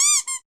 squeak.mp3